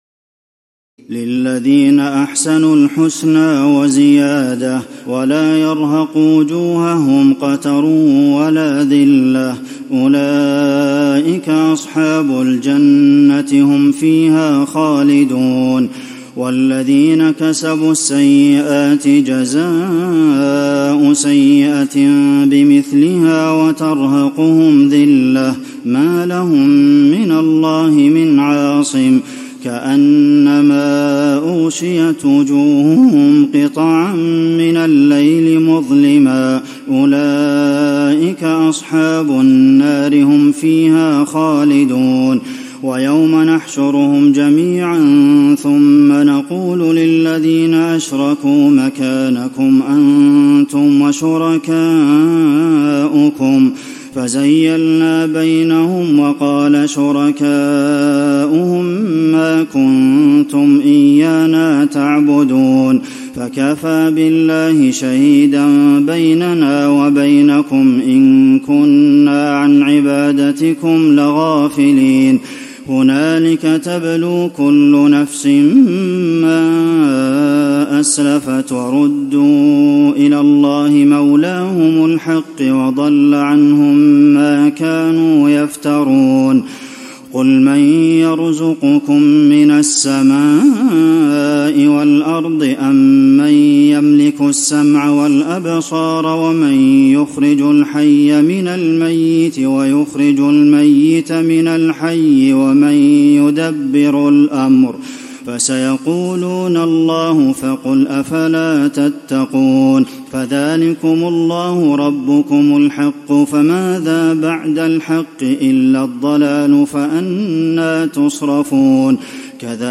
تراويح الليلة العاشرة رمضان 1435هـ من سورة يونس (26-109) Taraweeh 10 st night Ramadan 1435H from Surah Yunus > تراويح الحرم النبوي عام 1435 🕌 > التراويح - تلاوات الحرمين